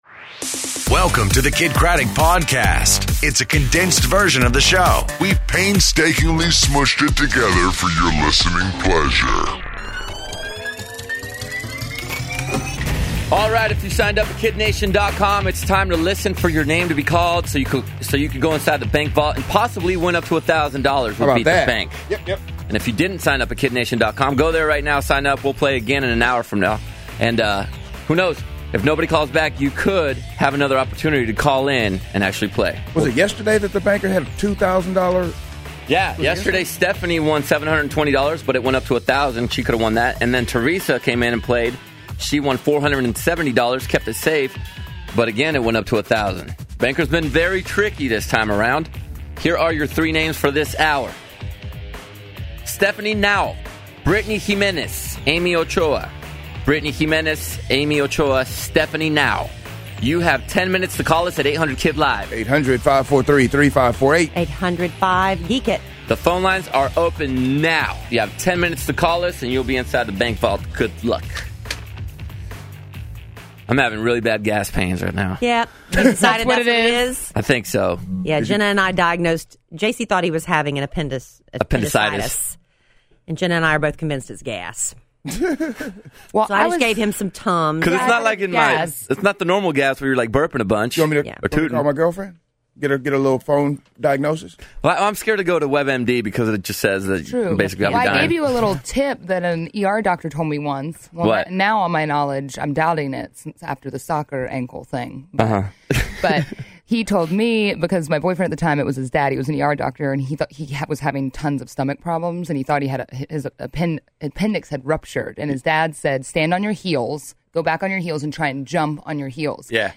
First World Problems, Tim Gunn On The Phone, And The New Apple iOS